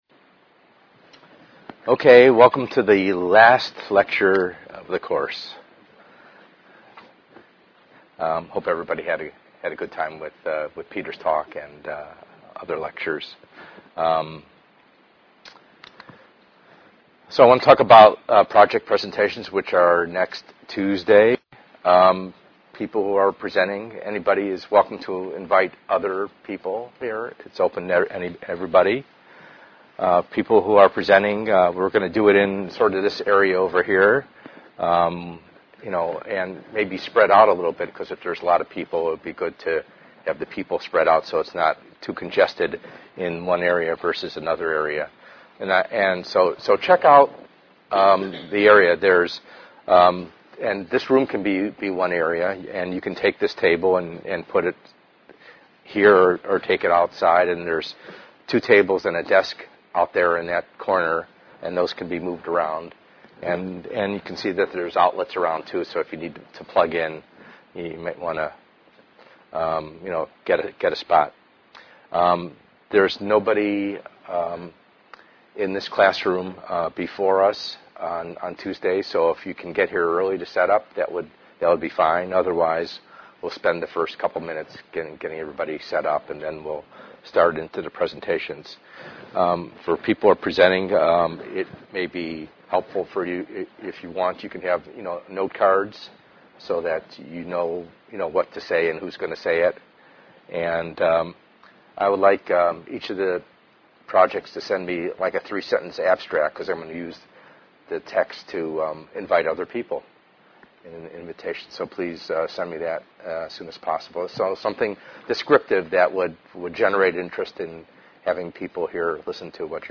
ENGR110/210: Perspectives in Assistive Technology - Lecture 9b